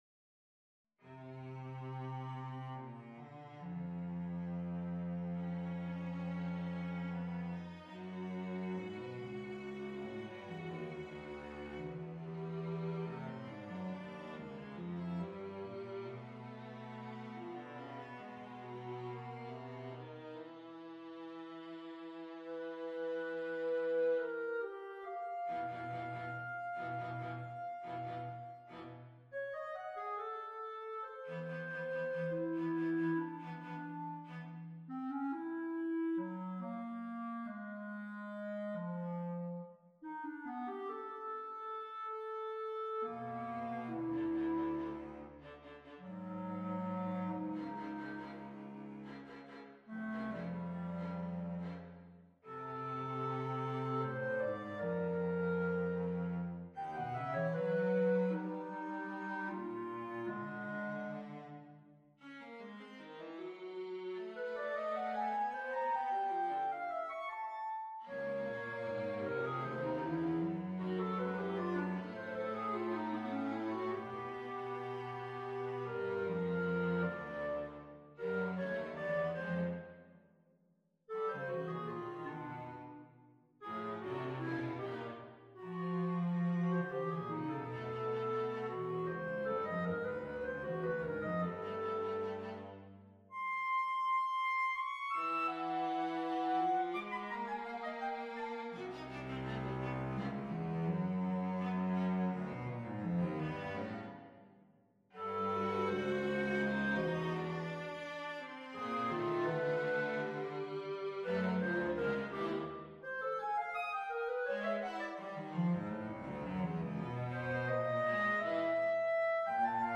for Clarinet, Viola and Violoncello
on a purpose-selected tone row
Allegro pensieroso - Allegro assai - Allegro molto - Presto ma non troppo - Tempo Terzo - Tempo Secondo - Tempo Primo